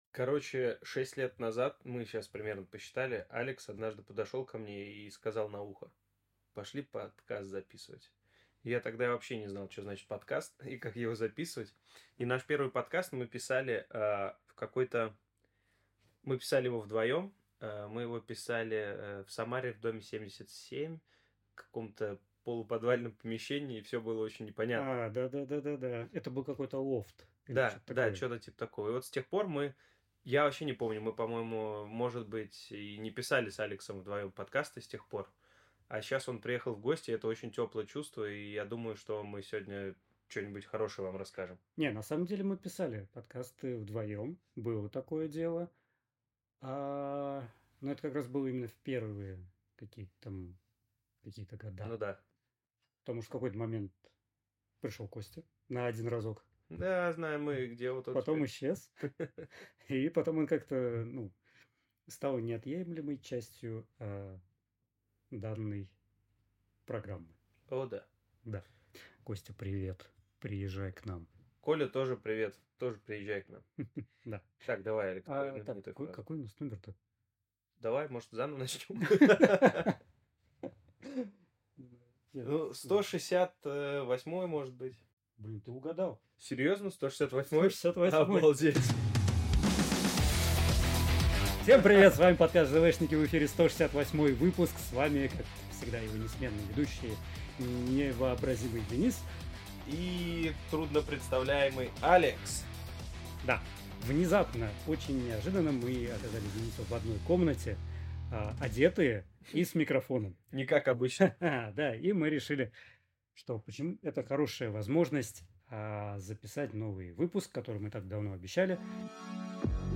Мы встретились лицом к лицу, чтобы поговорить про Асоку и другие мелочи.